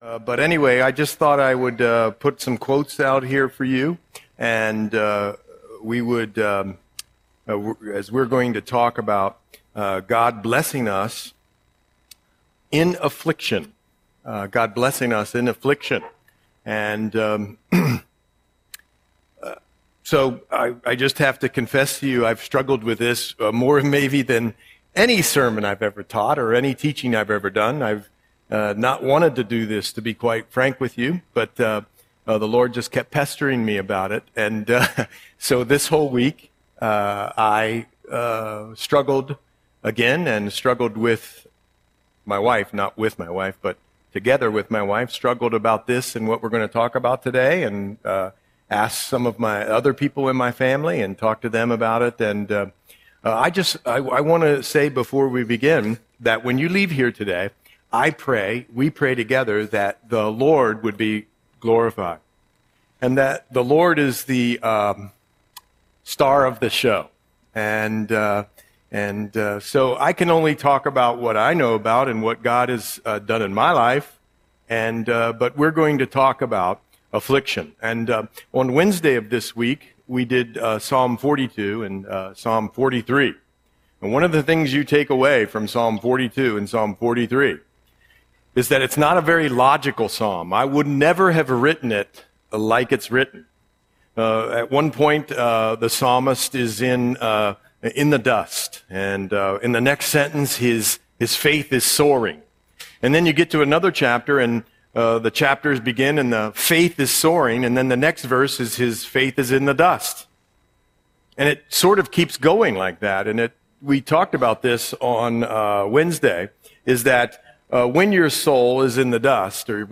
Audio Sermon - July 27, 2025